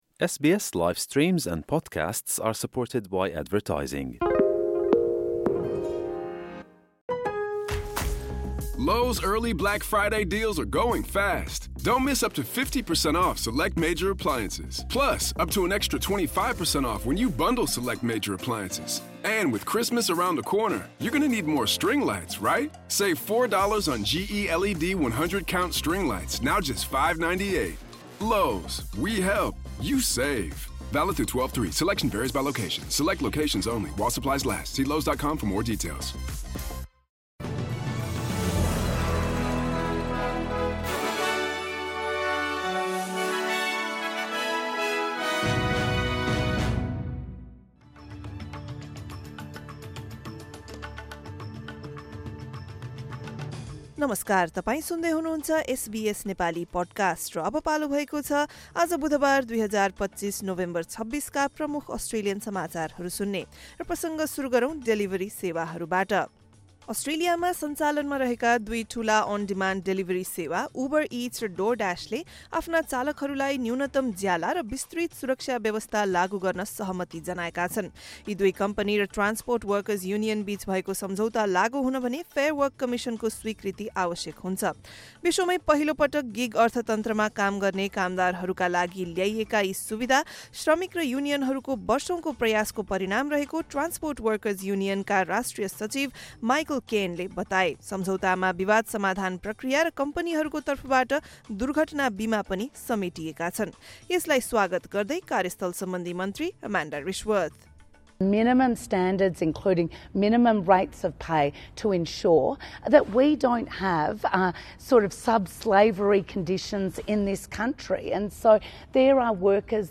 SBS Nepali Australian News Headlines: Wednesday, 26 November 2025